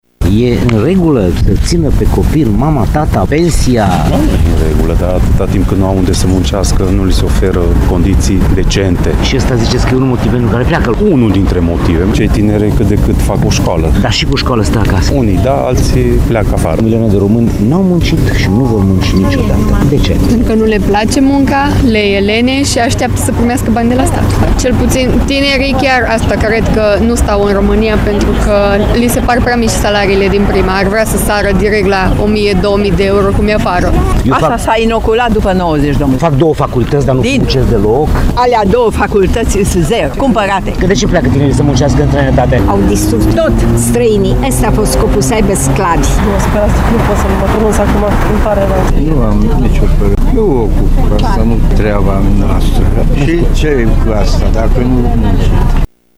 Târgumureșenii sunt de acord că nu e în regulă ca tinerii să stea acasă, dar susțin că nici statul nu le oferă oportunități.
Au fost și oameni care au refuzat să vorbească, deranjați că sunt întrebați despre acest subiect, al muncii: